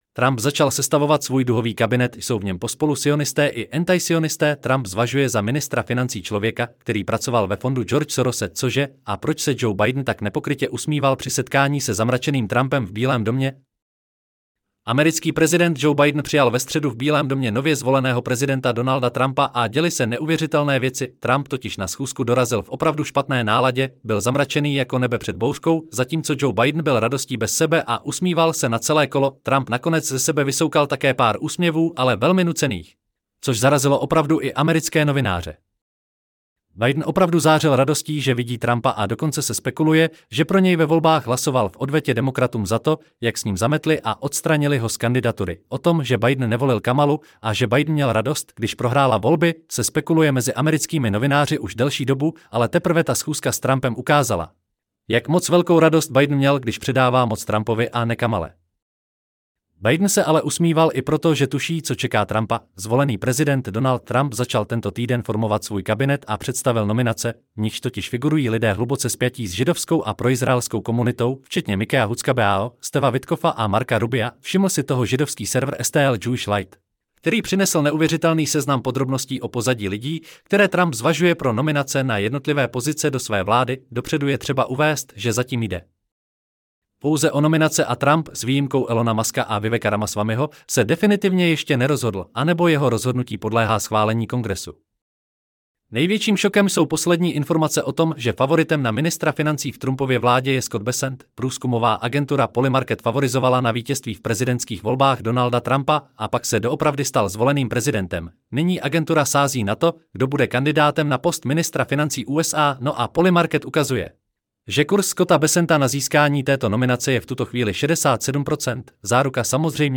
Celý článek si můžete poslechnout v audioverzi zde: Trump-zacal-sestavovat-svuj-duhovy-kabinetJsou-v-nem-pospolu-sioniste-i-anti-sionisteTrump 15.11.2024 Trump začal sestavovat svůj duhový kabinet!